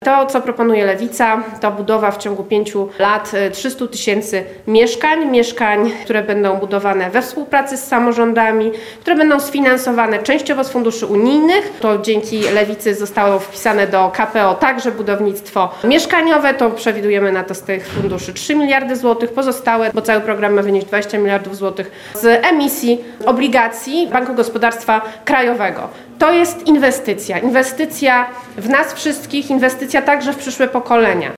O prawach młodych ludzi oraz programie dla młodych mówiły w Lublinie posłanki Nowej Lewicy - Agnieszka Dziemianowicz-Bąk i Anna Maria Żukowska.
Jak mówiła posłanka Żukowska, jednym z najbardziej angażujących młodych ludzi tematów jest kwestia własnego mieszkania, a na to odpowiedzią lewicy jest budowa mieszkań na tani wynajem.